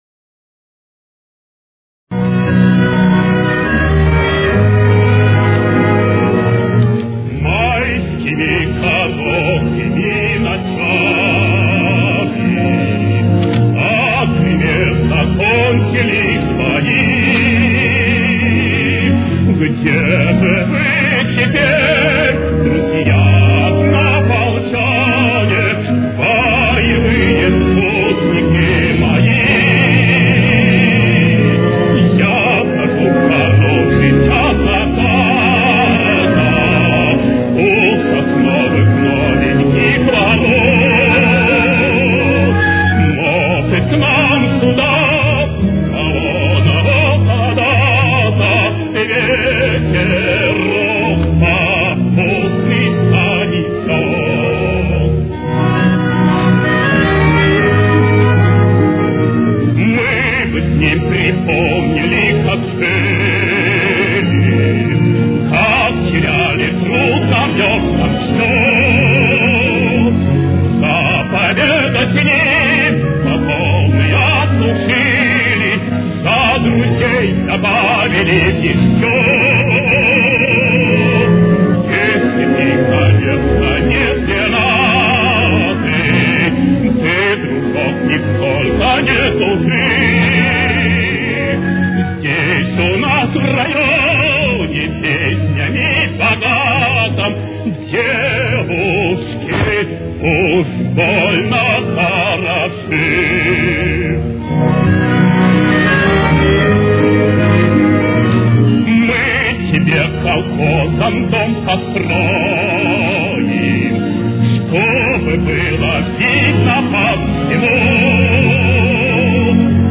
лирического баритона